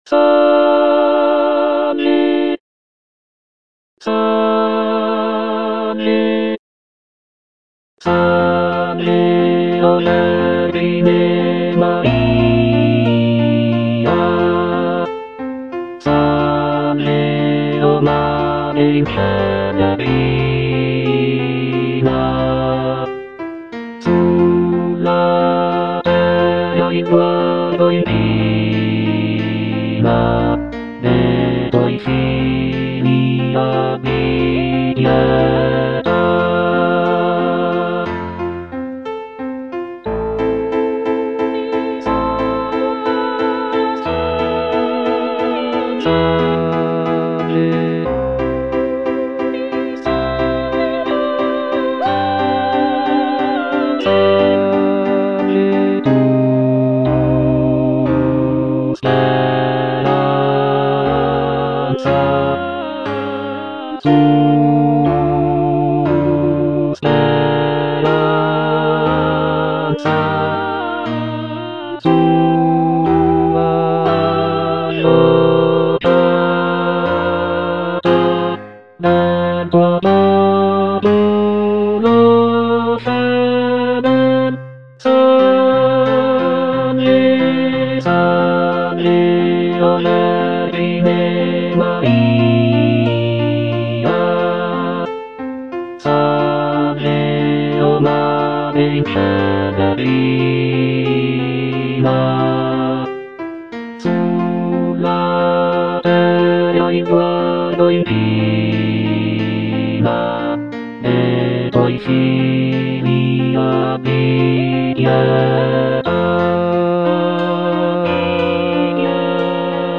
Bass (Emphasised voice and other voices) Ads stop
sacred choral work